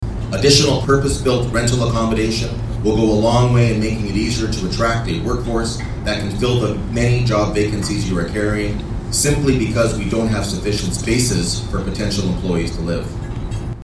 At Belleville’s Ramada Hotel, they welcomed the Municipality of Brighton to the event, which focused on thanking the business community, and talked about the changing workplace.